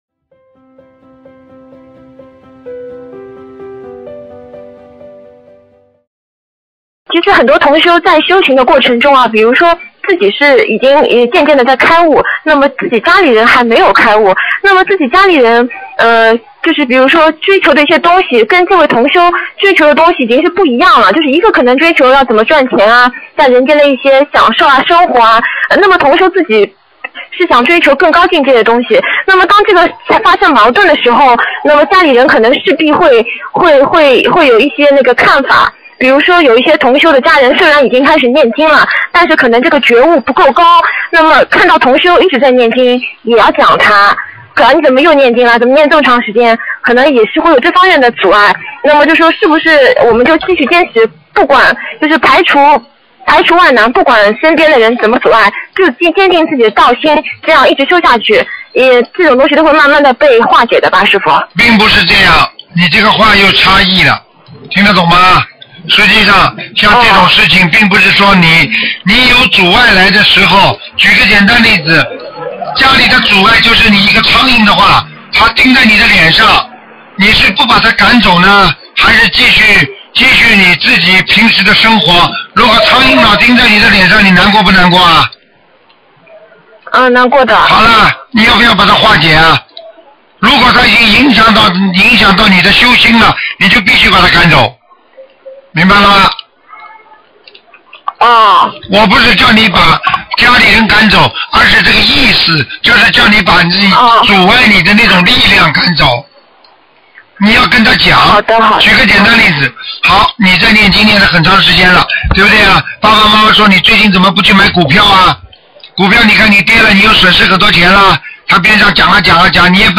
音频：修行当中遇到家人阻碍应该去化解《节目录音+字幕》玄艺问答 2013年2月15日